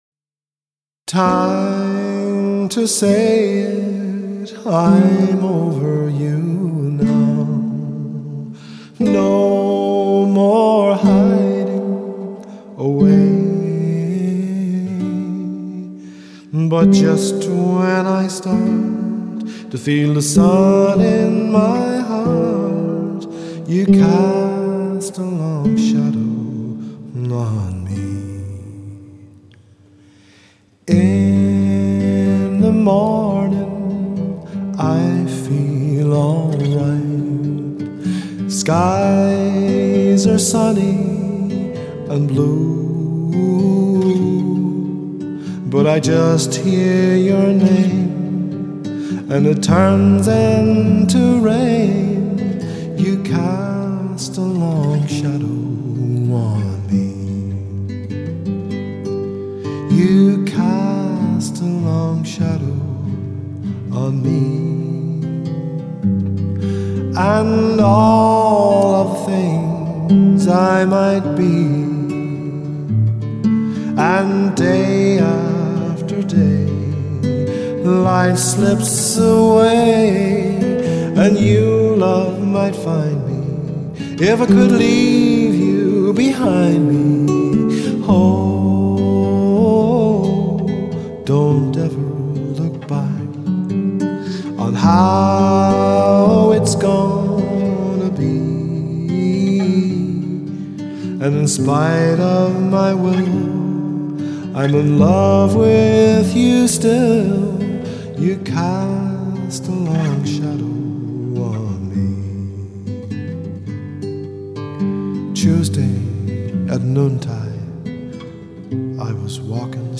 voice & guitar